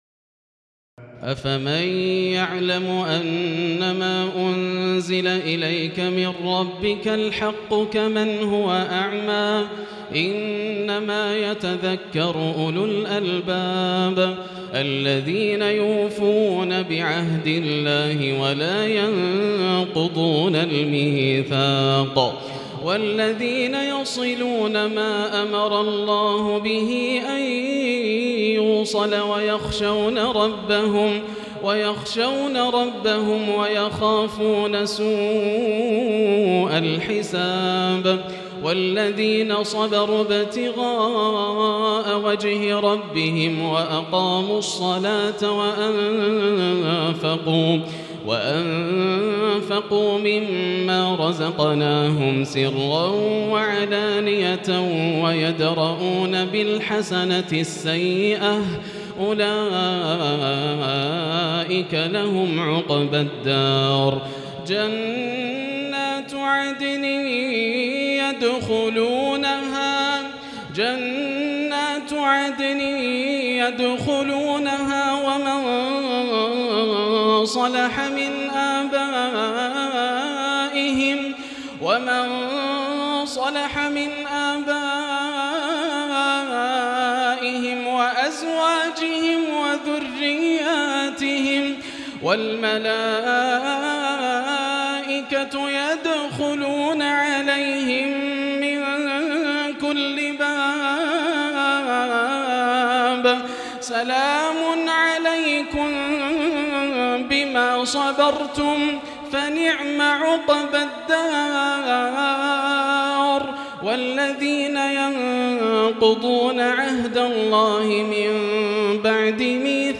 تراويح الليلة الثانية عشر رمضان 1438هـ من سورتي الرعد (19-43) و إبراهيم كاملة Taraweeh 12 st night Ramadan 1438H from Surah Ar-Ra'd and Ibrahim > تراويح الحرم المكي عام 1438 🕋 > التراويح - تلاوات الحرمين